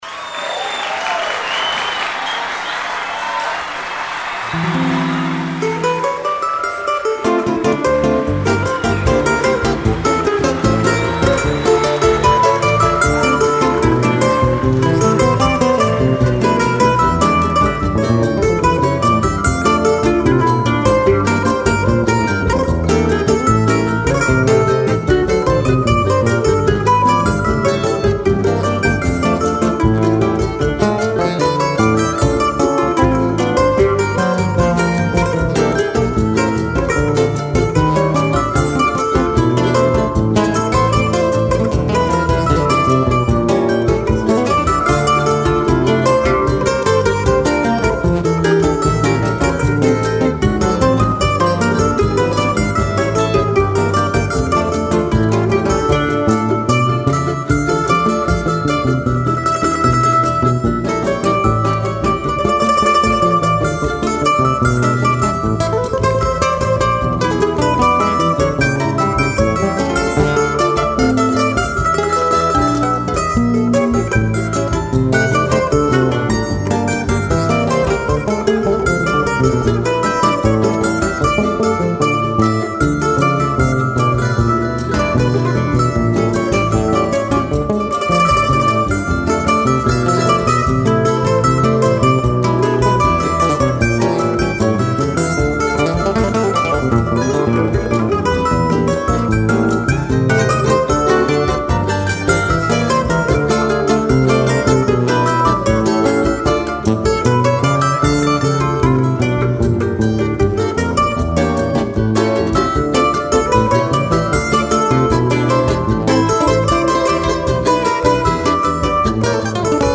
1138   03:24:00   Faixa:     Modinha